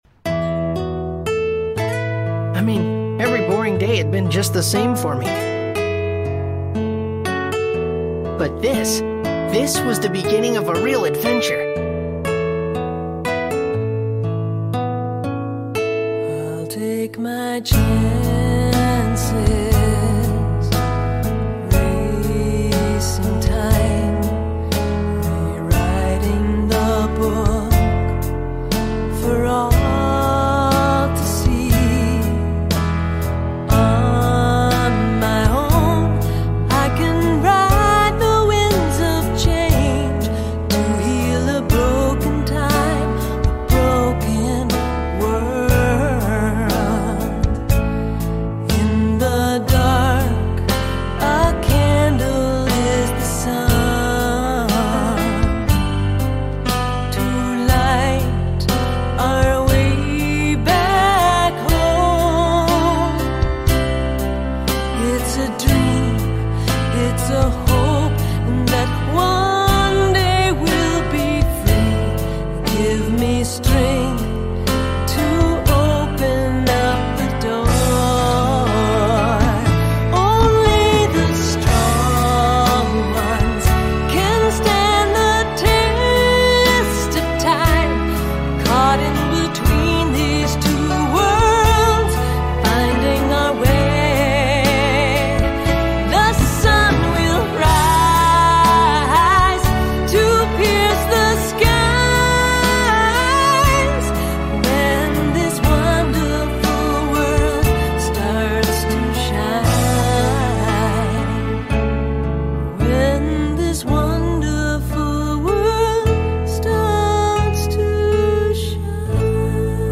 Prologue scene